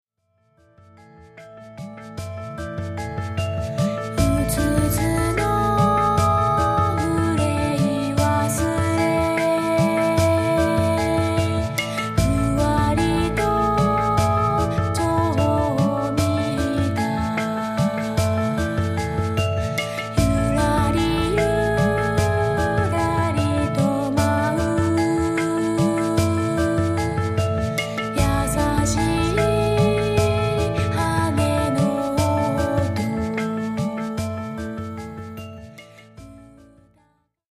インストを中心にコーラスも織り交ぜた計5曲、20分程度のオリジナル曲を収録。
「聞き込む音楽」というよりは、さりげなく部屋で聞く音楽を目指しました。
ジャンル： EasyListening, NewAge